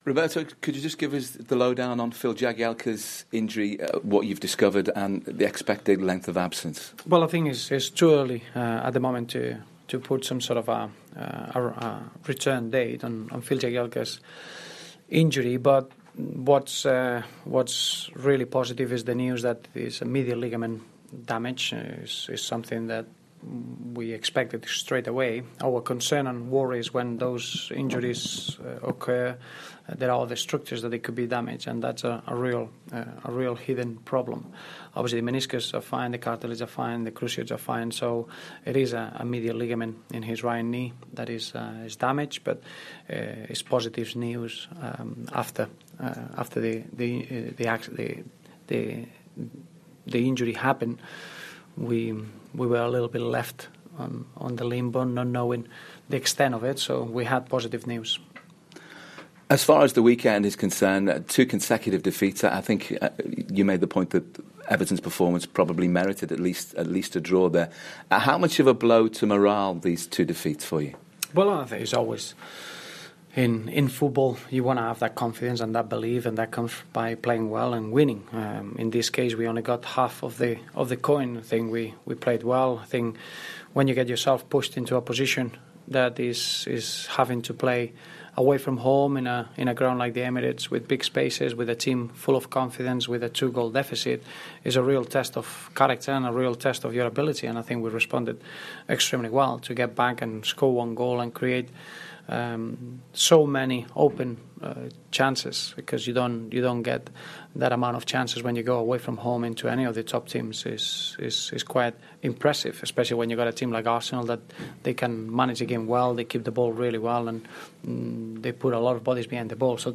Roberto Martinez pre-Norwich Press Conference
Roberto Martinez speaks to the media ahead of Everton's Capital One Cup tie with Norwich.